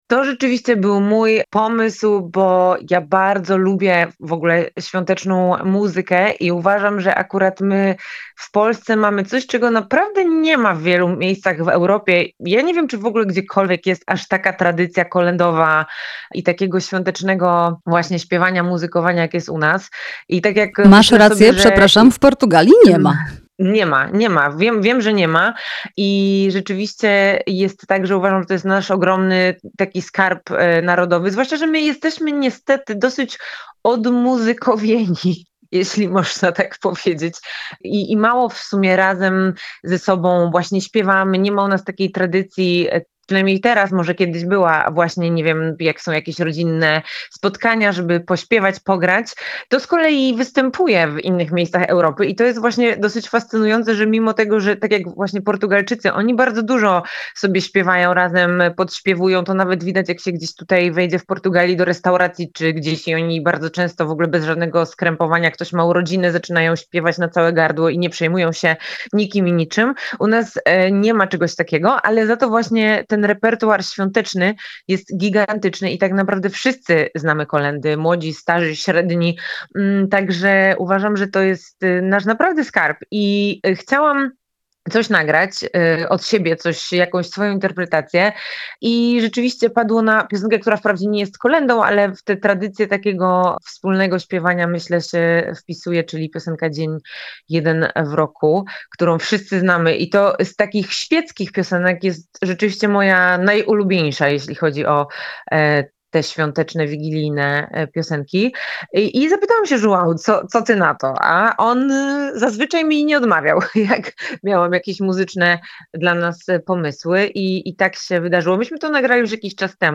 „Dzień jeden w roku” [POSŁUCHAJ ROZMOWY]